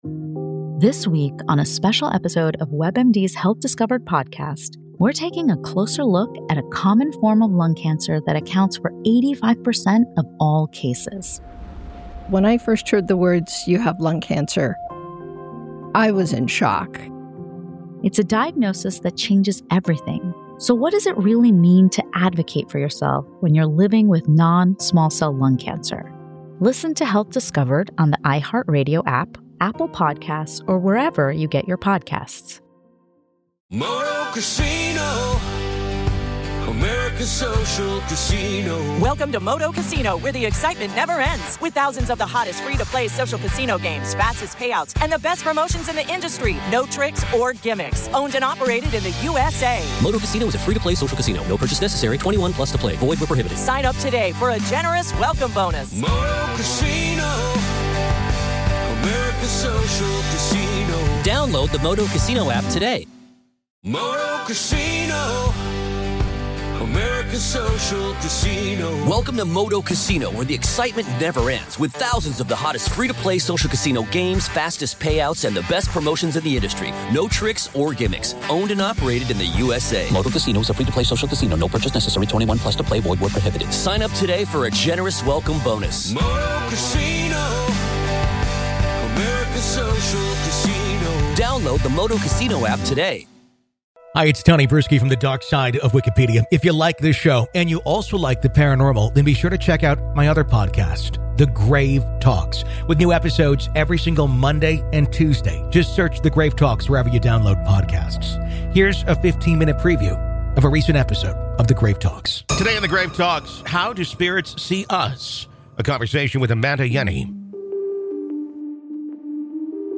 Today an in-depth conversation with her about the origins and path that spirits take today.